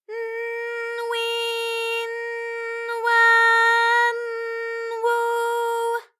ALYS-DB-001-JPN - First Japanese UTAU vocal library of ALYS.
w_n_wi_n_wa_n_wu_w.wav